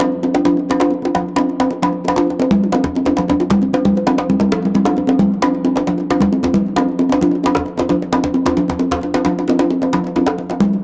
CONGABEAT8-R.wav